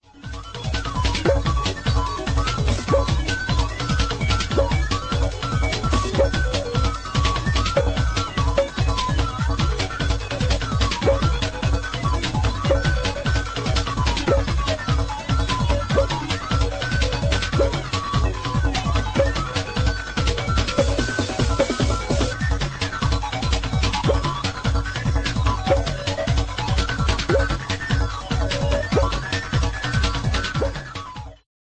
Acid-techno
Techno Detroit